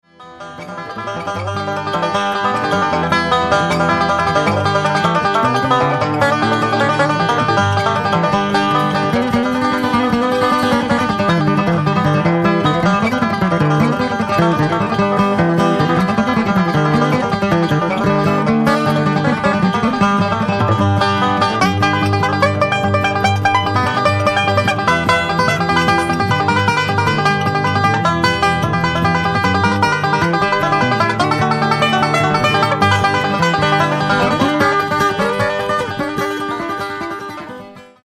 60年代からＮＹ/東海岸を拠点にそれぞれ活動していた、2人のシンガーと3人のインストゥルメンタリストからなる5人組。
バンジョー、フィドル、スティール・ギター